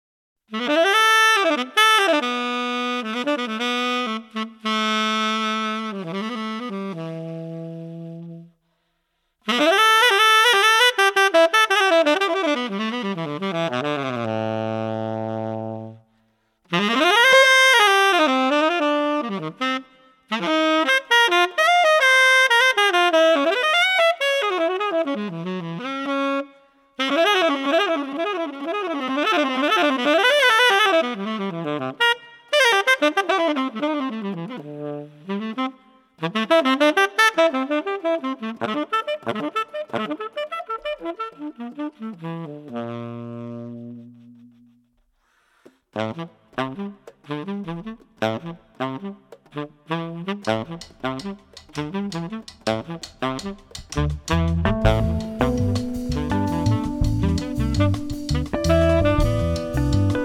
saxophoniste
d’une forte intensité, aussi bien colorée que percutante